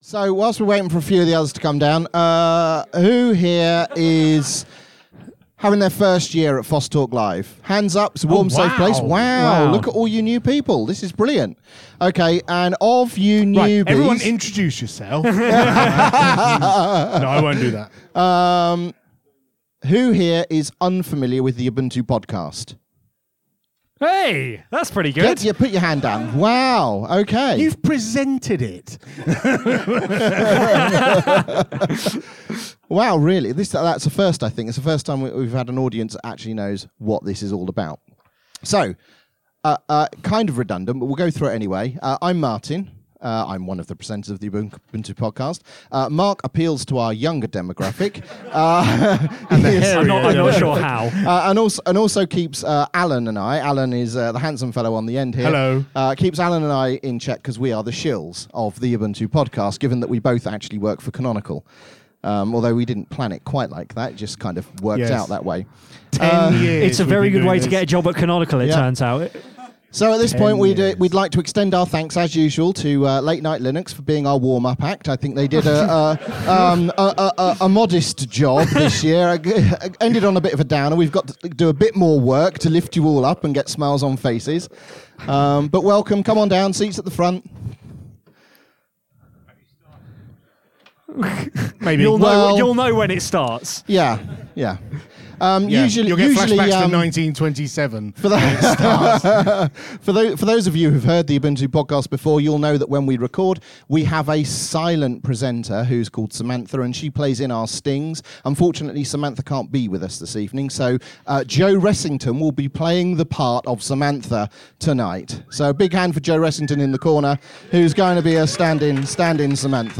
This show was recorded in front of a live studio audience at FOSS Talk Live on Saturday 9th June 2018! We take you on a 40 year journey through our time trumpet and contribute to some open source projects for the first time and discuss the outcomes.